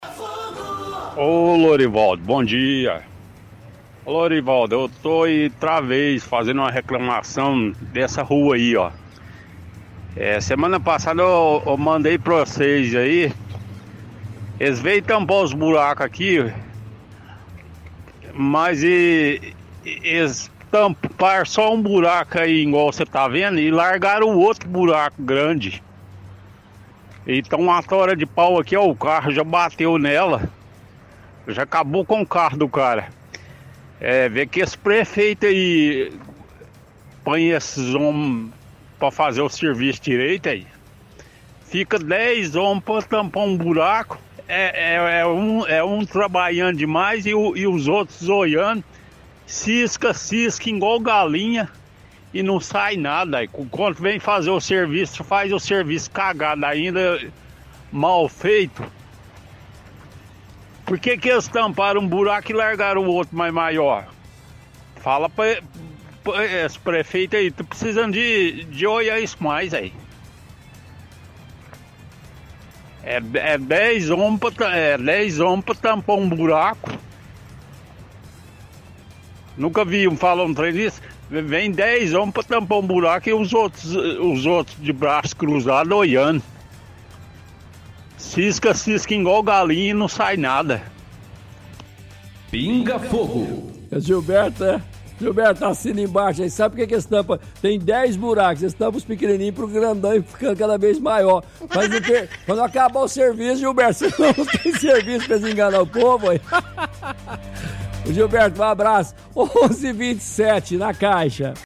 – Ouvinte reclama de operação tapa buracos, dizendo que ficam buracos sem reparo e muitos funcionários da equipe não fazem nada.